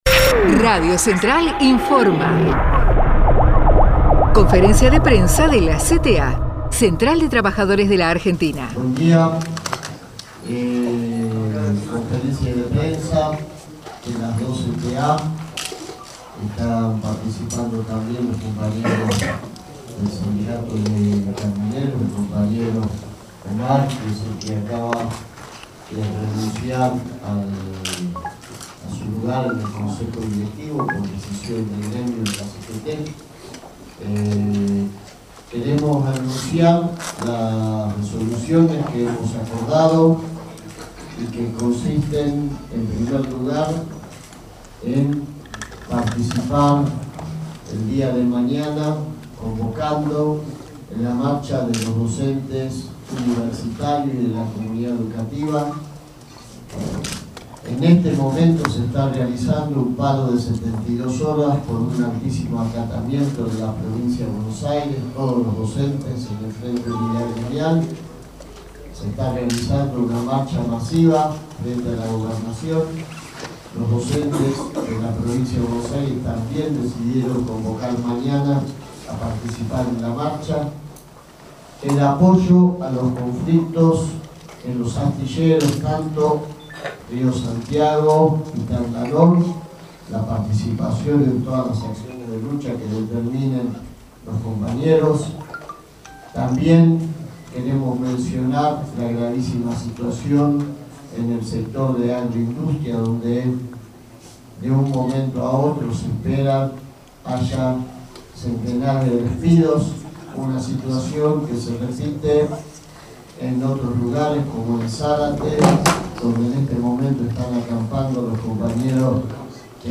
Desde el Salón Germán Abdala de la CTA de los Trabajadores, las CTA también convocaron a manifestarse el día que el gobierno nacional trate el presupuesto que, en palabras de Yasky, estará atado a las disposiciones del FMI.